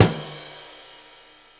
LOOP39SD08-R.wav